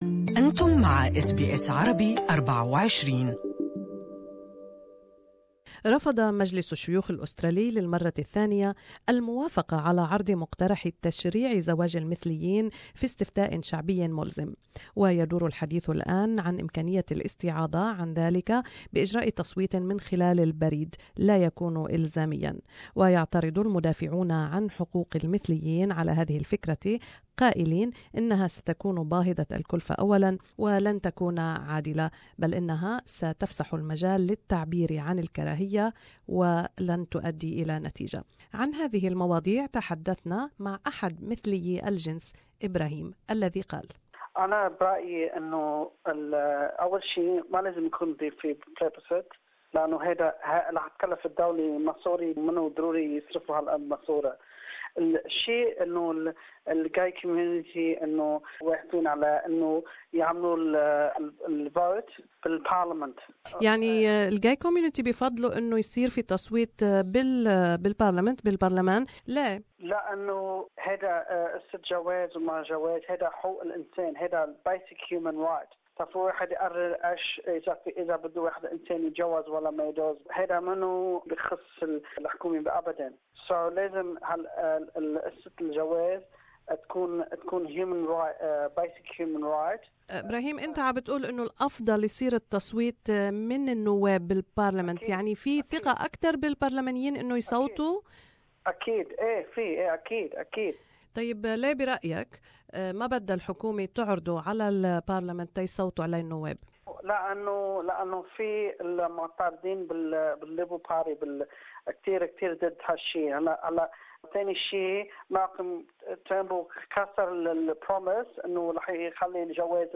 Labor, the Greens and crossbenchers have blocked the government's proposed plebiscite on same-sex marriage in the Senate. It means the government will now hold a postal vote. Gay man tells us his opinion.